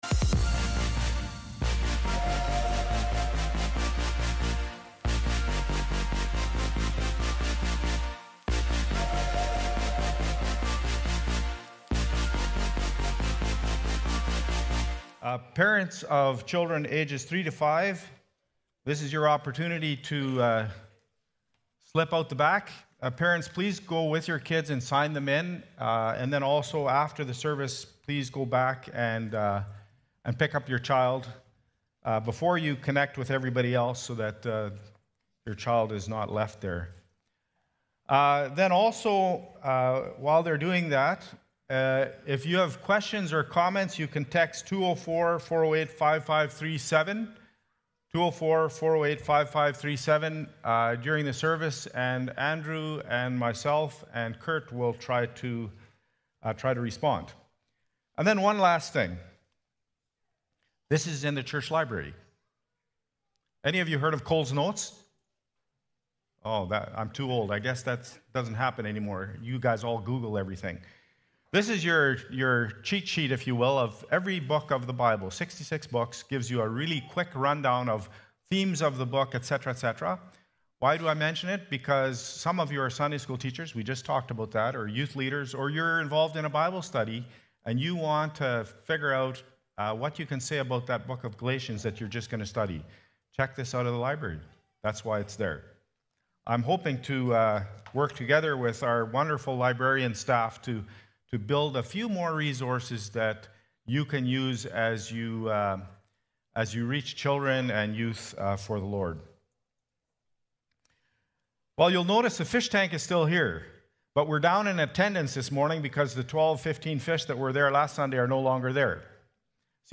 Past Message
Sept-15-Worship-Service.mp3